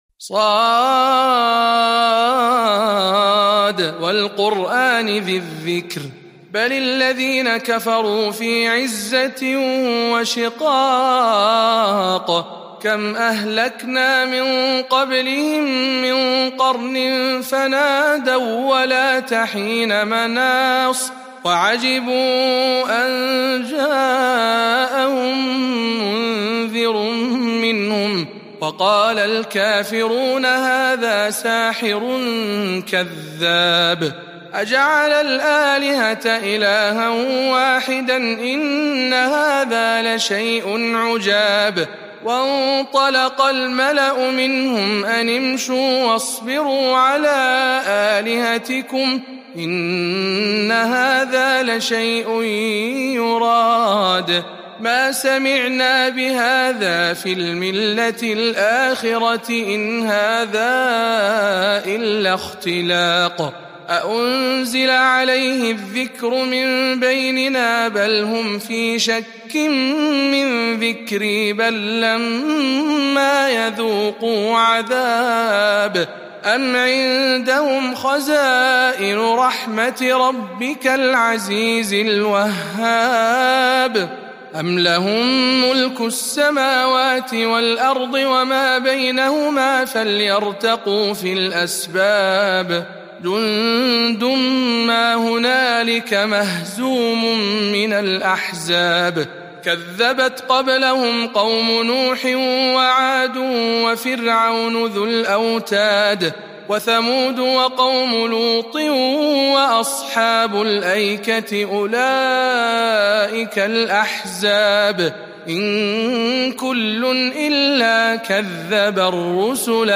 037. سورة ص برواية شعبة عن عاصم - رمضان 1441 هـ